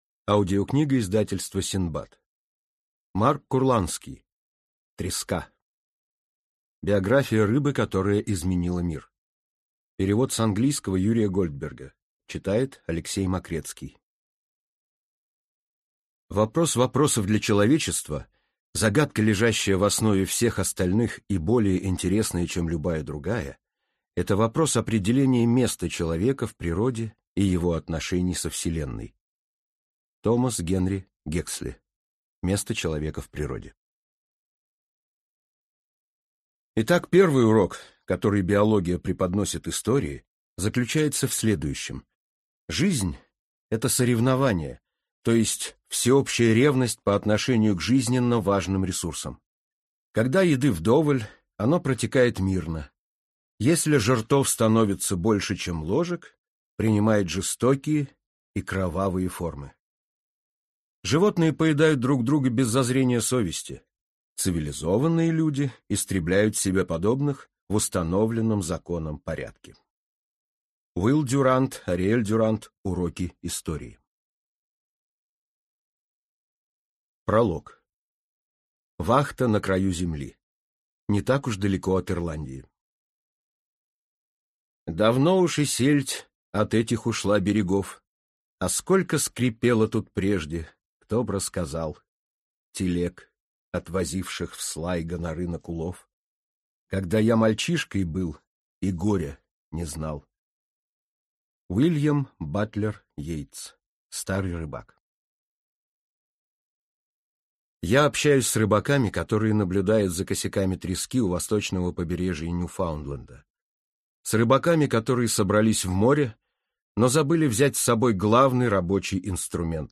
Аудиокнига Треска. Биография рыбы, которая изменила мир | Библиотека аудиокниг